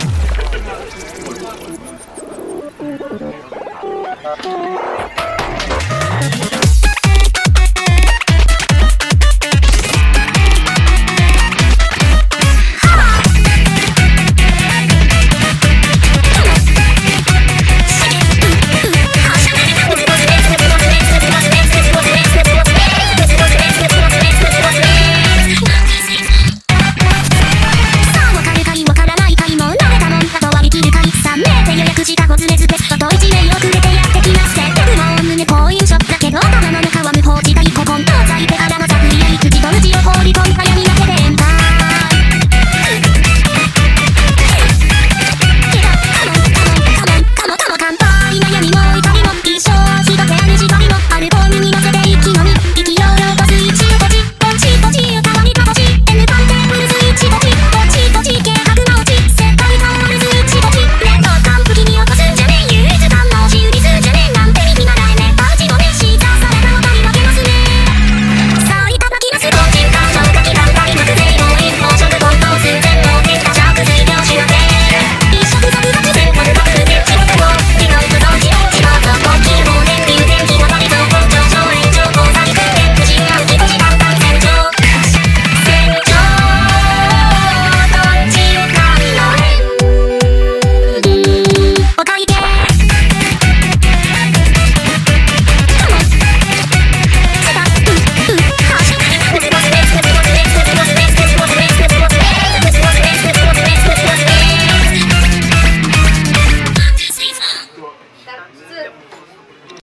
frenetic piece